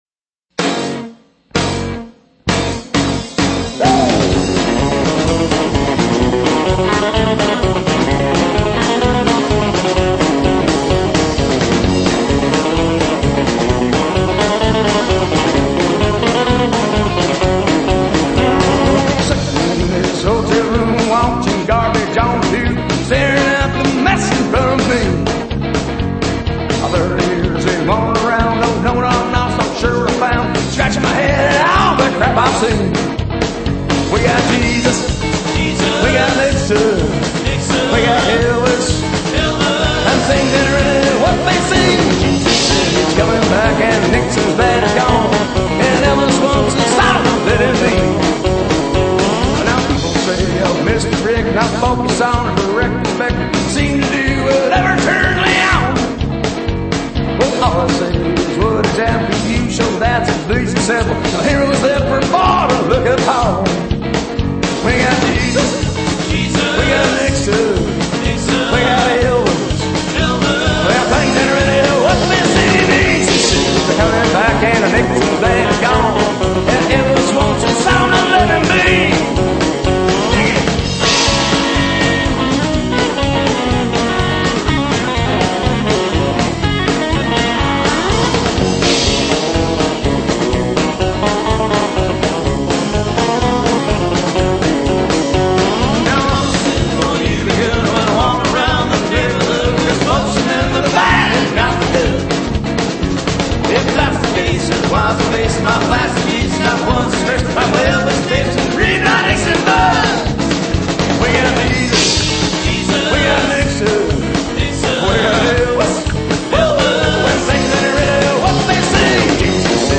2 min. lo-fi sample MP3s!